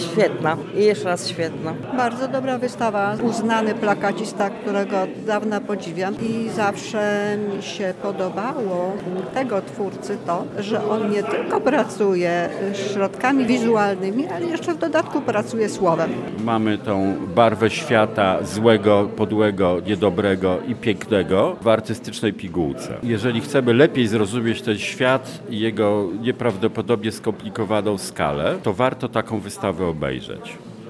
Zapytaliśmy uczestników, jakie emocje w nich wzbudził.
Relacja z wernisażu